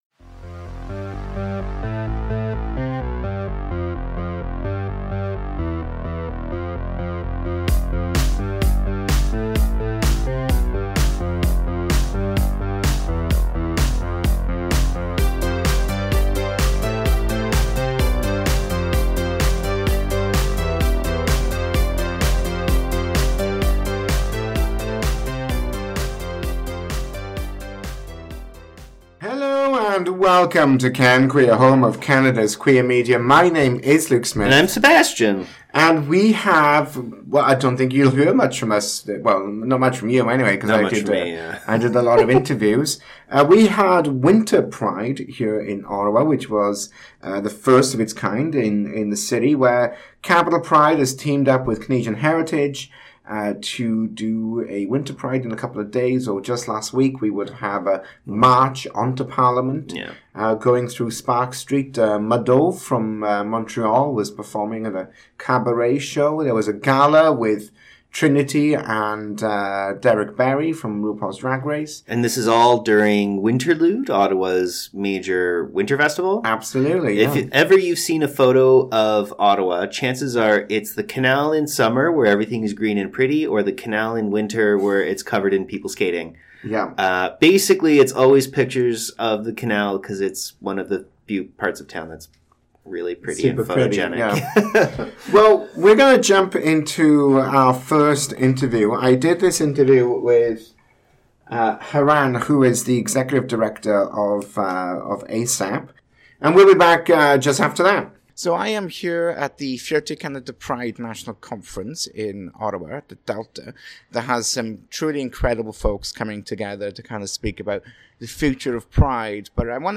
interview a number of guests at the Fierte Canada Pride Conference